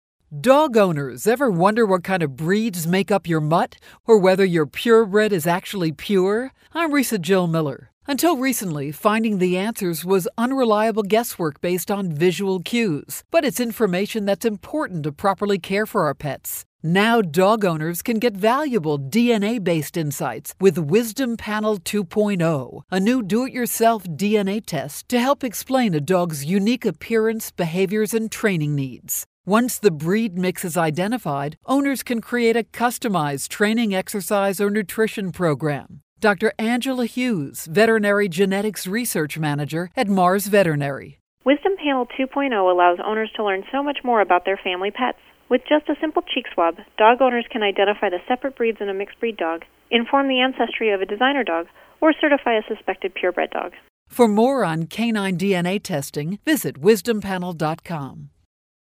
April 23, 2013Posted in: Audio News Release